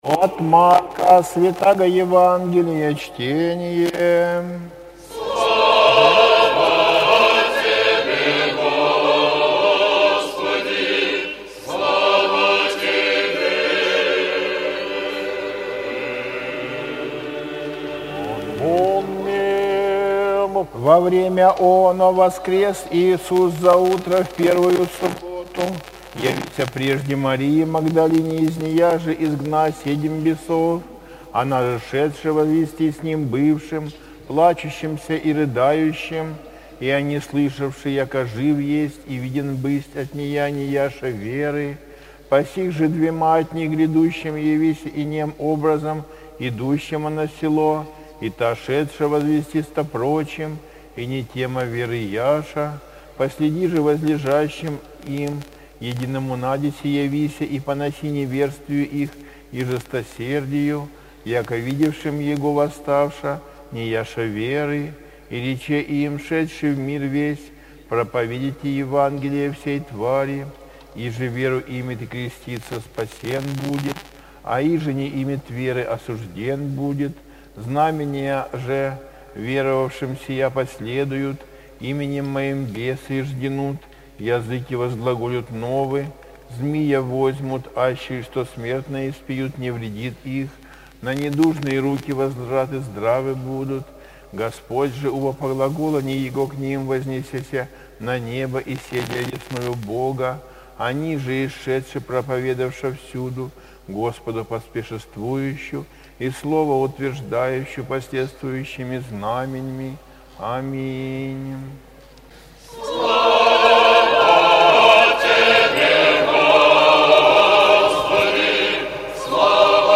ЕВАНГЕЛЬСКОЕ ЧТЕНИЕ НА УТРЕНЕ